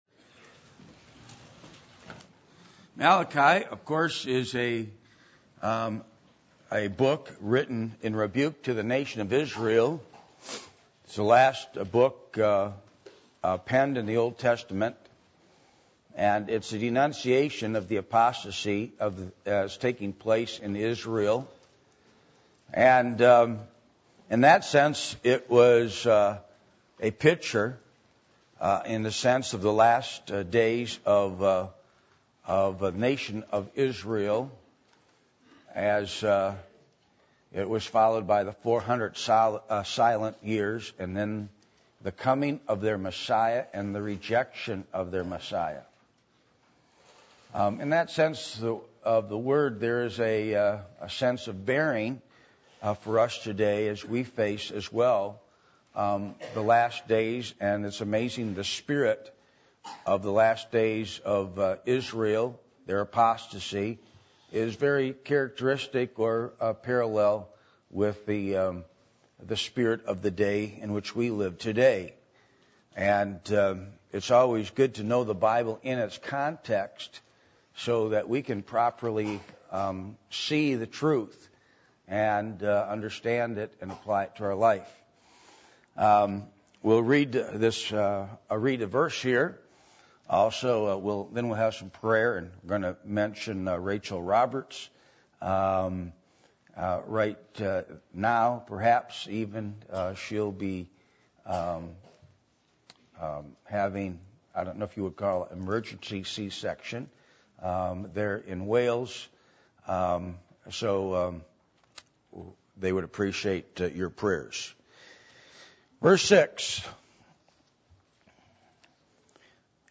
Malachi 1:6-8 Service Type: Sunday Evening %todo_render% « The Grace Of Our Lord Jesus Christ What Will Bring You True Satisfaction?